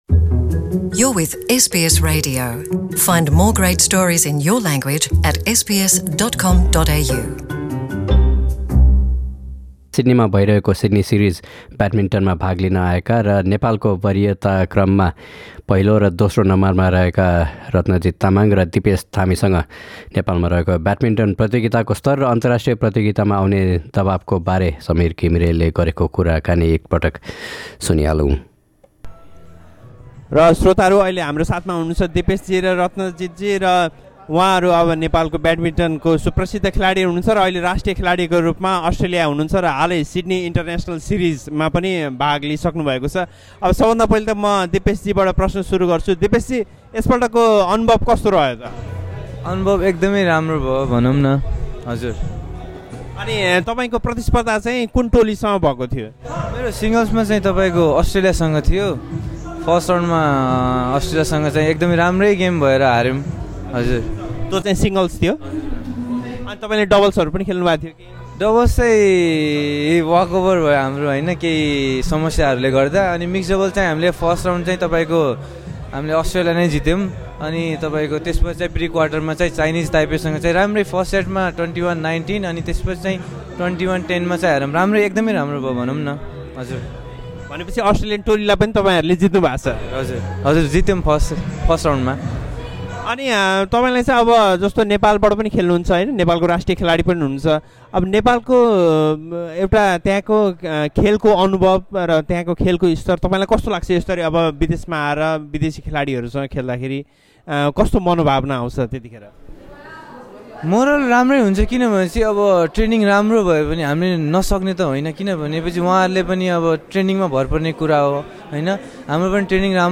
आफ्नो खेल र नेपालमा ब्याडमिन्टन प्रतिको रुचिबारे उहाँहरूले एसबीएस नेपालीसँग गरेको कुराकानी।